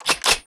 WEAP CLI02.wav